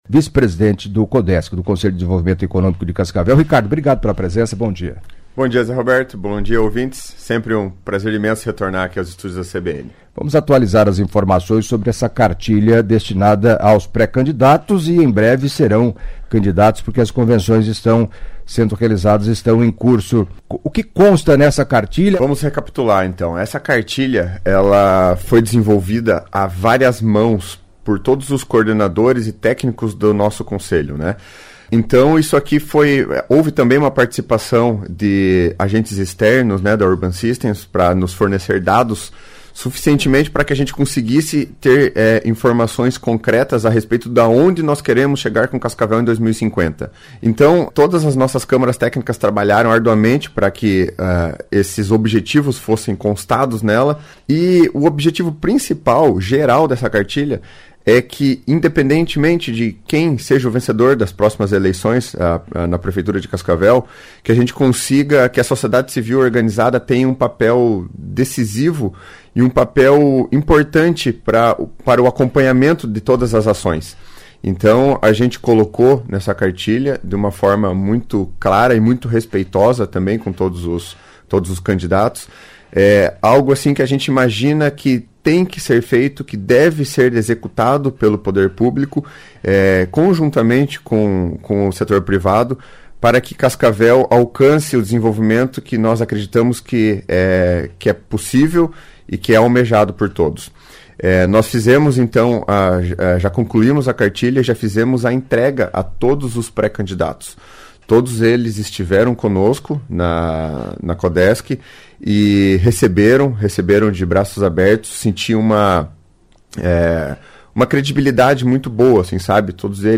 Em entrevista à CBN nesta quinta-feira (25)
respondeu dúvidas de ouvintes